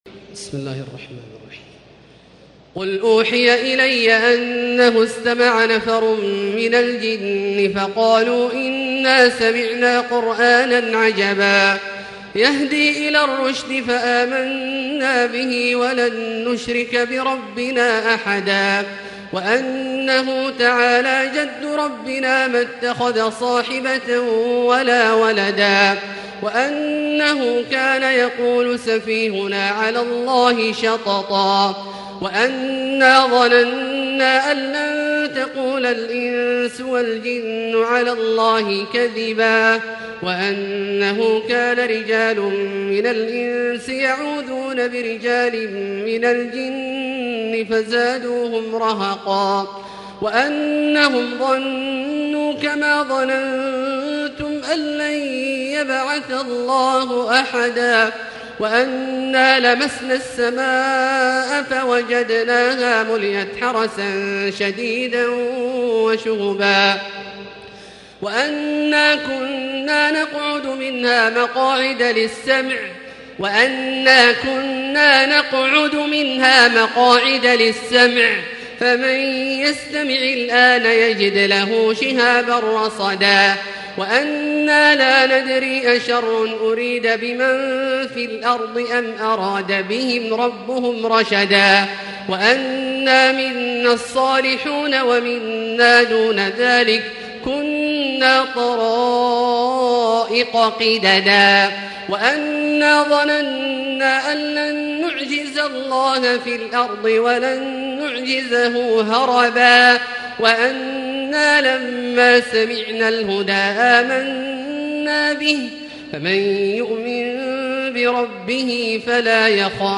تراويح ليلة 28 رمضان 1440هـ من سورة الجن الى المرسلات Taraweeh 28 st night Ramadan 1440H from Surah Al-Jinn to Al-Mursalaat > تراويح الحرم المكي عام 1440 🕋 > التراويح - تلاوات الحرمين